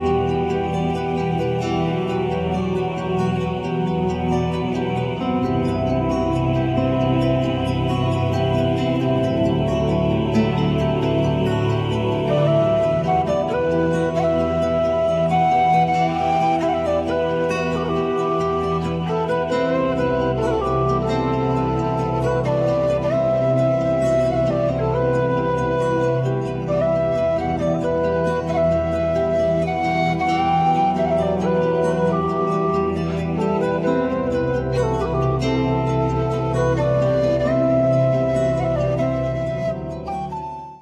skrzypce
whistle, wokal, akordeon, bombarda, bansuri
tabla, darabuka, djembe, cajón, instrumenty perkusyjne
gitara akustyczna
gitara basowa
perkusja